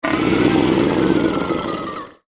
furious_03.mp3